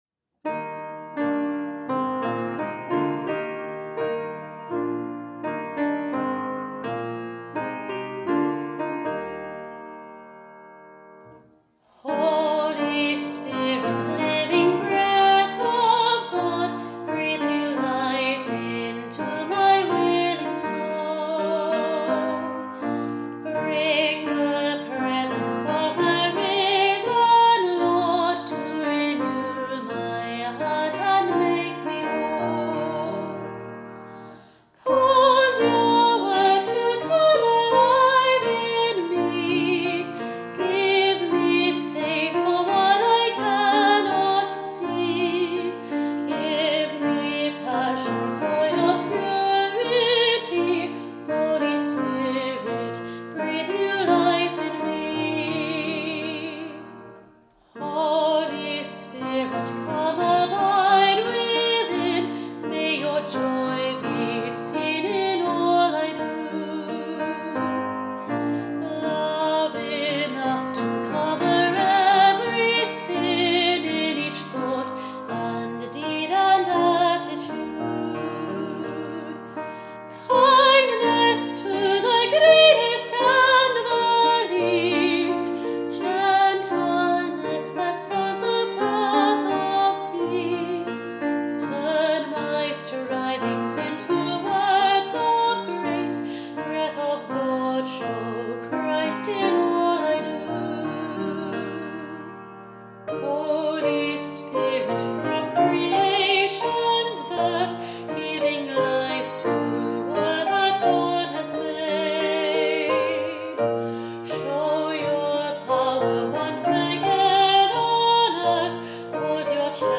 Final Hymn Holy Spirit, living breath of God